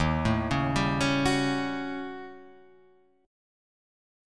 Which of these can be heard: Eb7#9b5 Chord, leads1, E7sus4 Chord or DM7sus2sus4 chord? Eb7#9b5 Chord